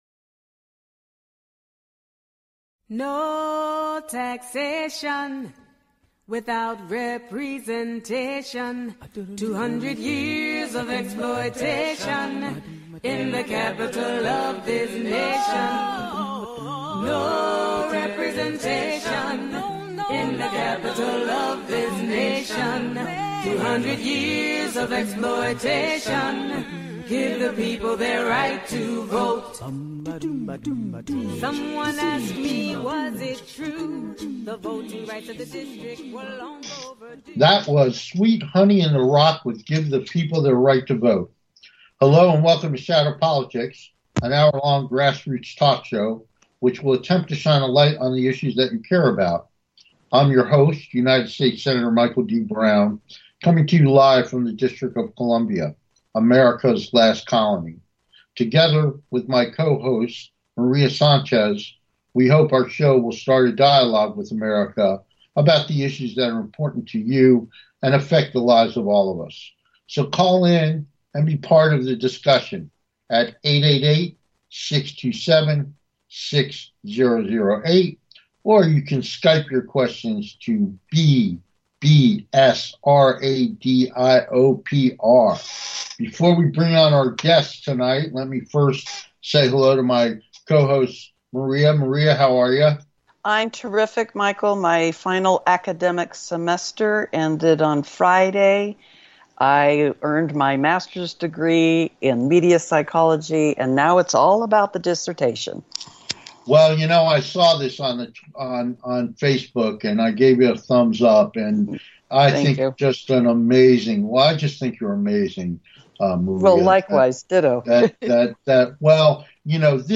Our guest is Duke University Professor William Darity Speaking about Reparations.